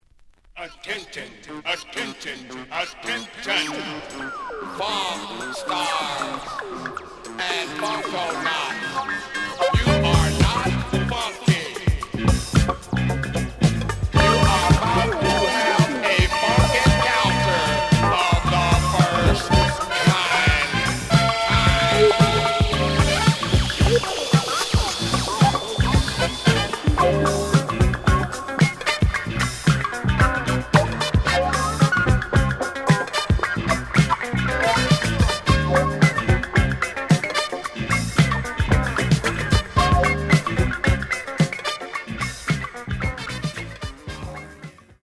The audio sample is recorded from the actual item.
●Genre: Funk, 70's Funk
Slight edge warp. But doesn't affect playing. Plays good.)